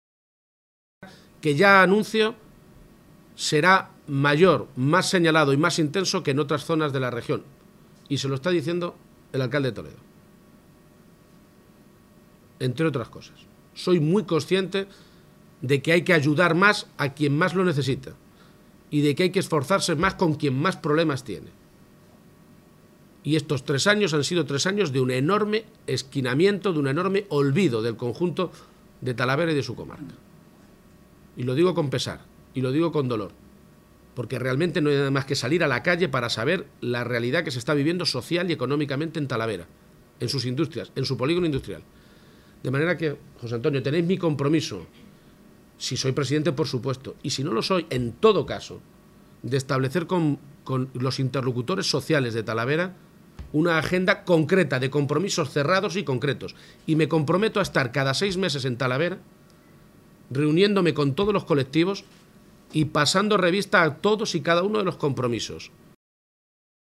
García-Page se pronunciaba de esta manera esta mañana, en Talavera de la Reina, tras reunirse con la Junta Directiva de la Federación Empresarial Talaverana (FEPEMPTA), en la ciudad de la cerámica.